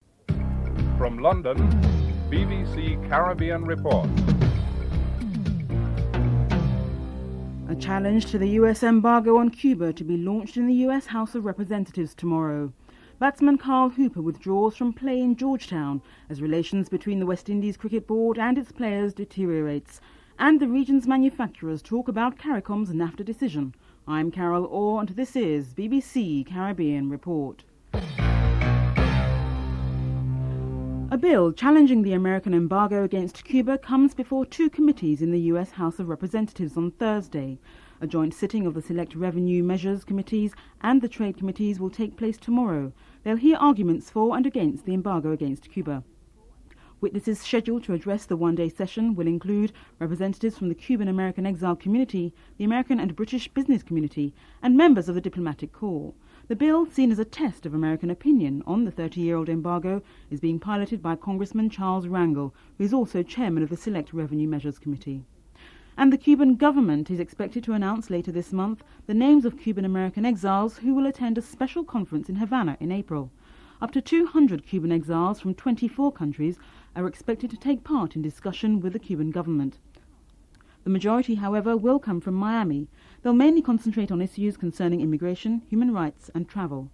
9. Theme music (14:37-14:51)